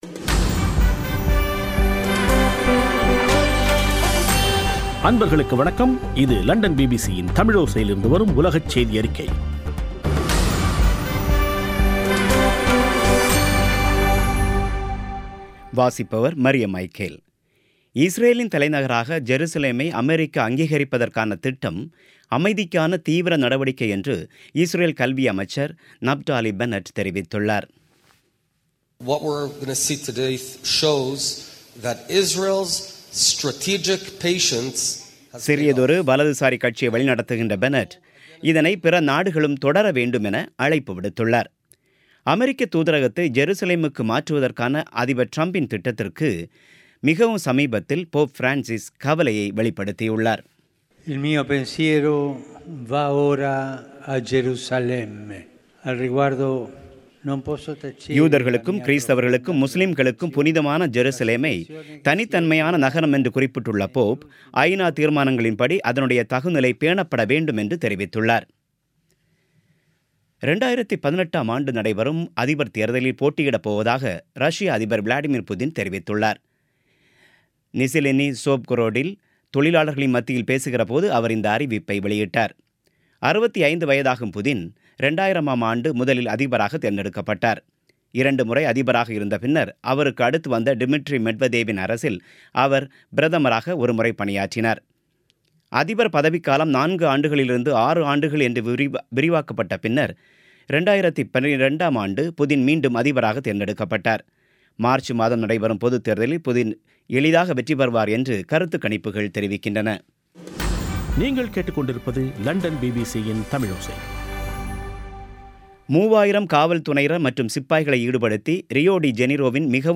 பிபிசி தமிழோசை செய்தியறிக்கை (06/12/2017)